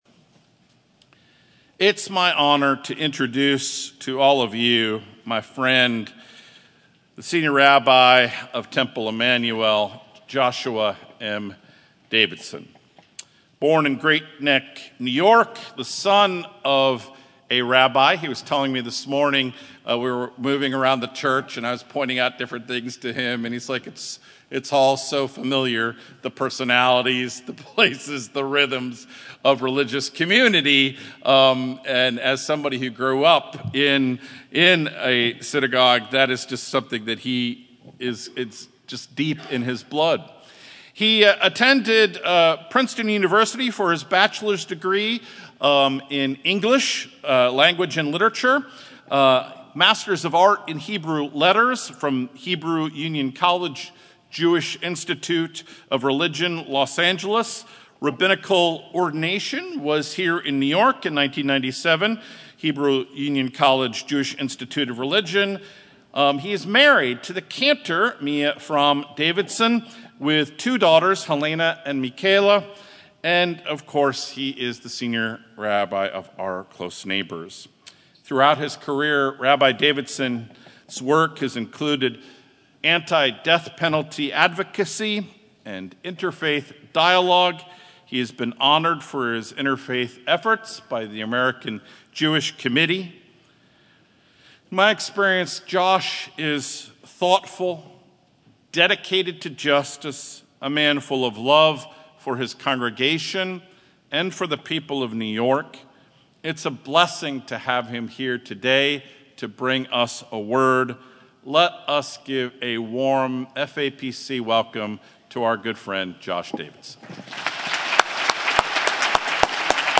Fourth Sunday of Easter